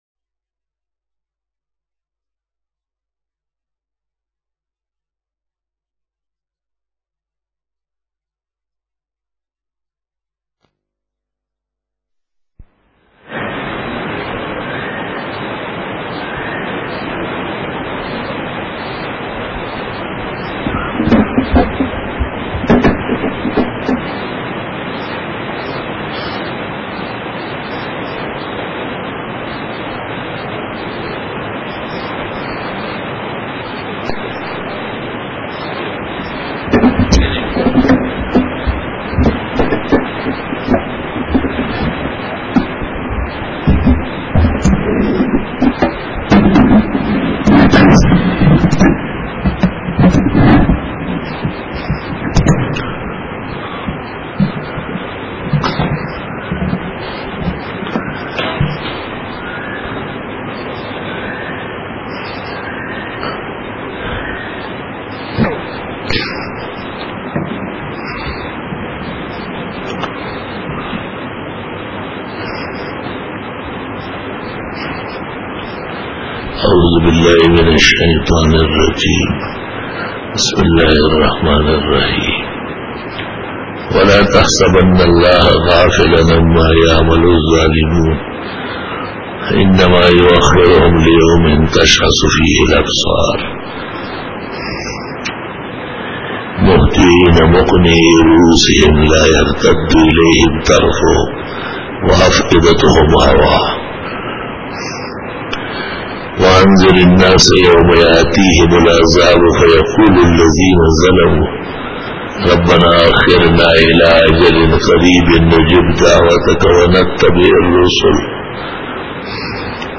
Khitab-e-Jummah